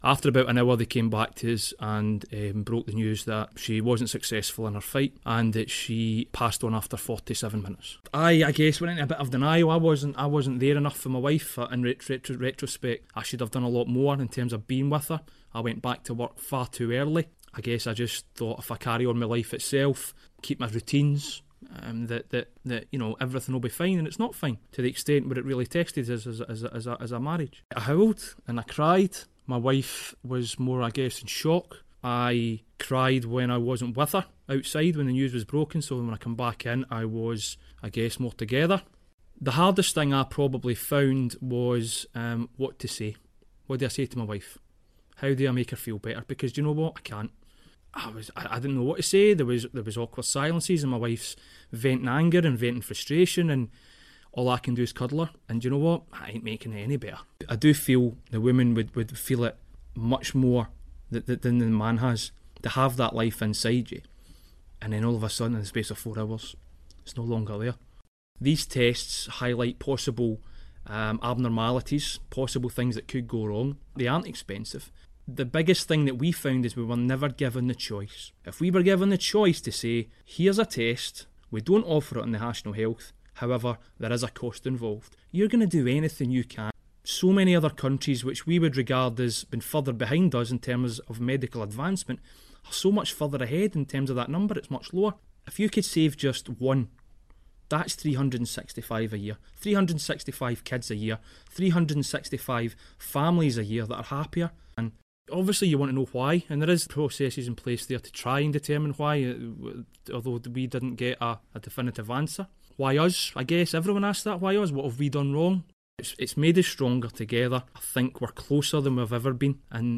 Dunure man speaks on Baby Loss Awareness week